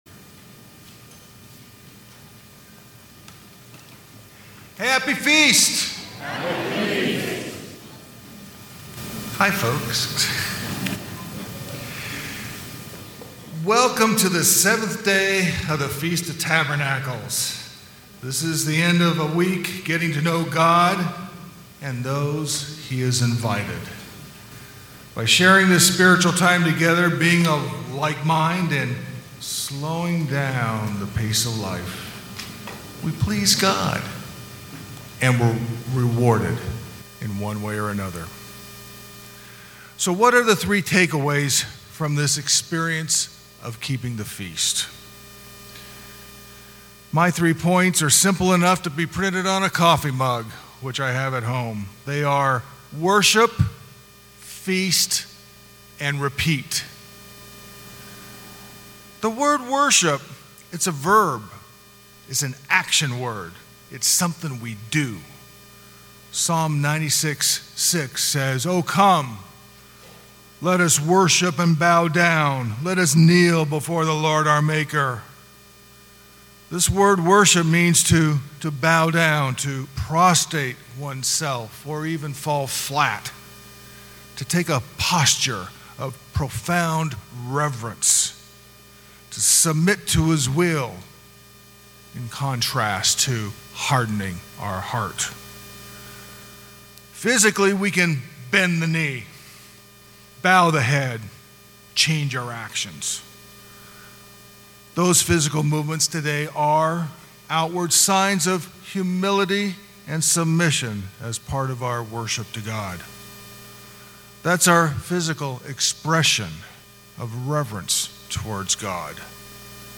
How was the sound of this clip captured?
Given in Temecula, California